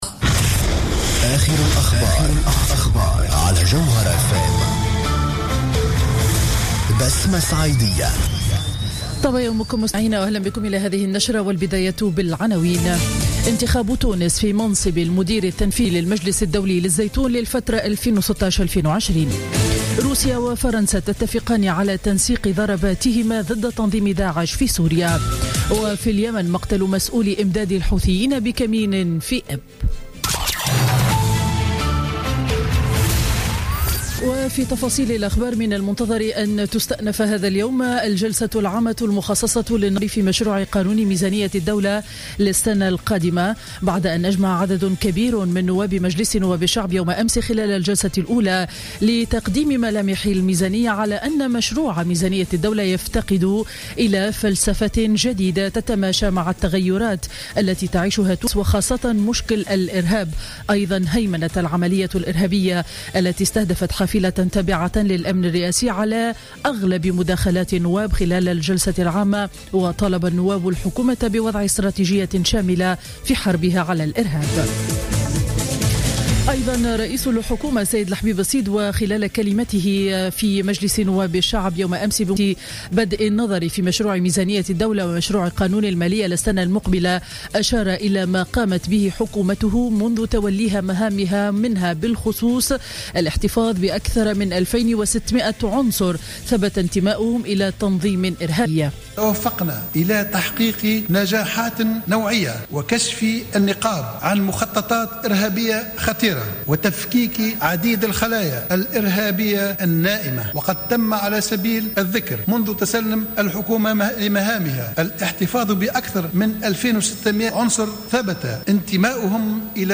نشرة أخبار السابعة صباحا ليوم الجمعة 27 نوفمبر 2015